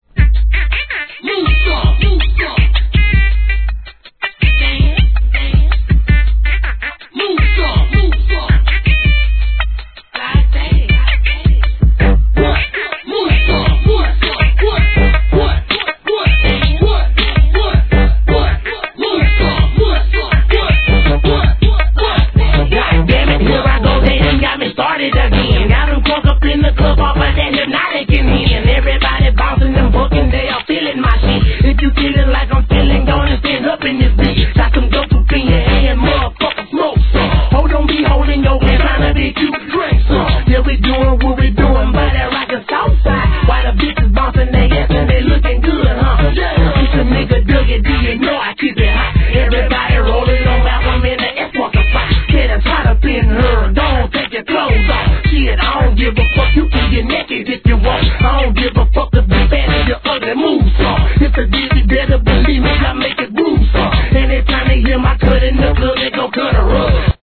G-RAP/WEST COAST/SOUTH
がなりフロウがはまったSOUTH BOUNCE!!